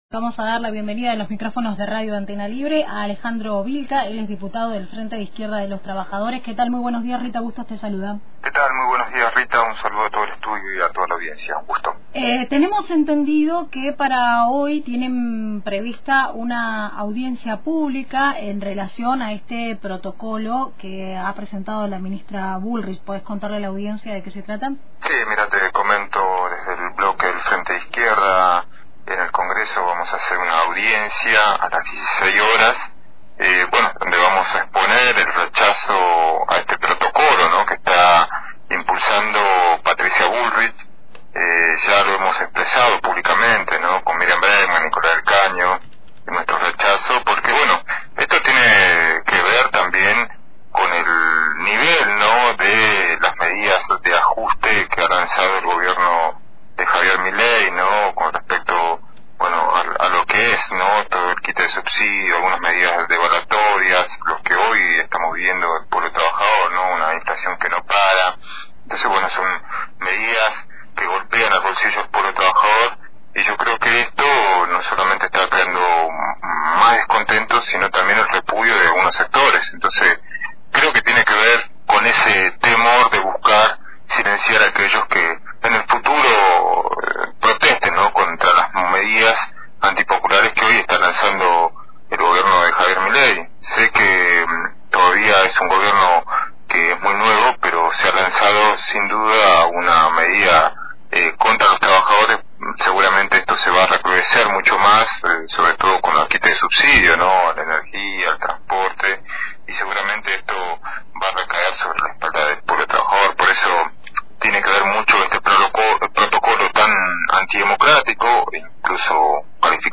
Esta mañana dialogamos con Alejandro Vilca, Diputado Nacional del Frente de Izquierda de los trabajadores sobre las actividades programadas en vísperas de los 22 años del estallido social del 2001,…